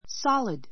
solid sɑ́lid サ り ド 形容詞 固体の; 堅 かた い, がっしりした; 中身が詰 つ まっている Water is liquid, but it turns solid when it freezes.